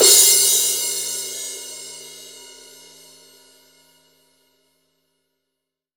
18 CRASH.wav